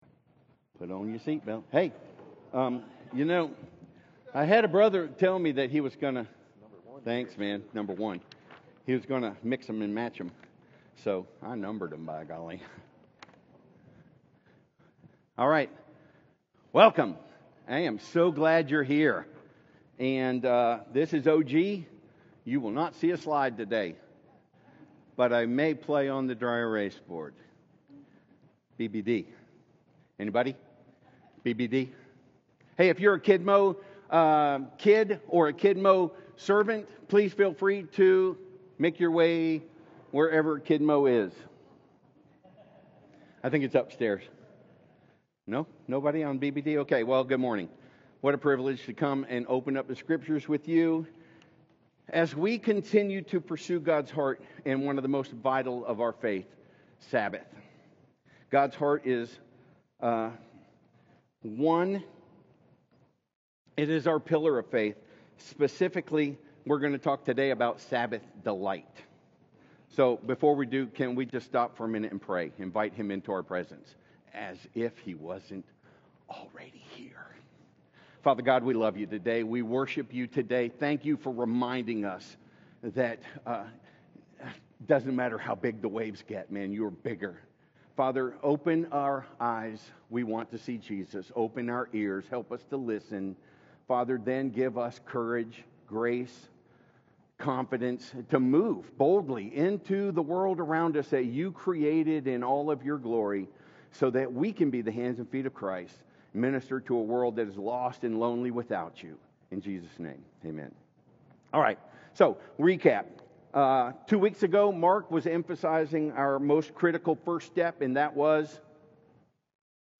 Journey Church Sermons